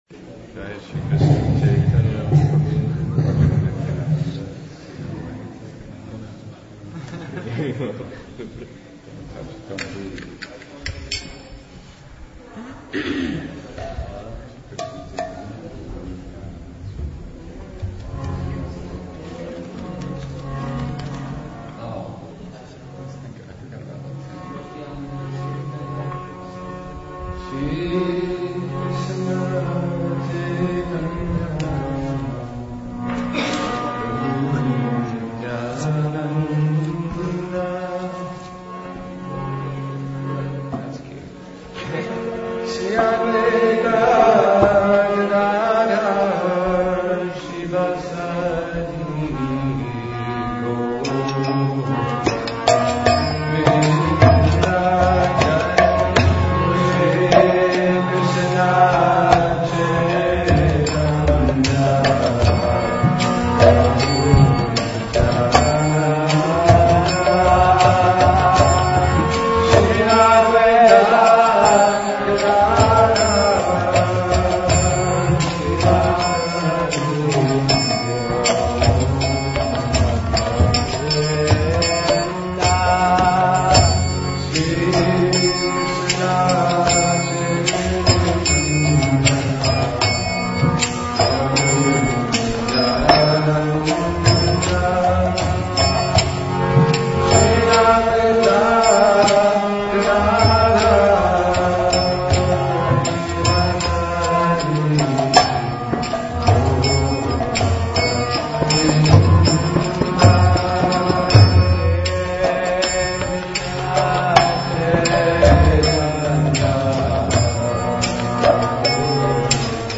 Kirtana
2008 September Namamrita Experience